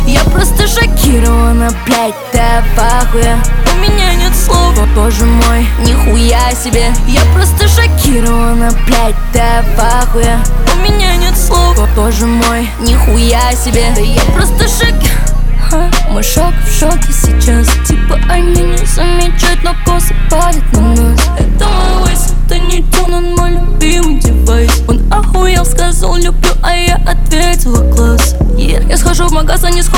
Жанр: Иностранный рэп и хип-хоп / Рэп и хип-хоп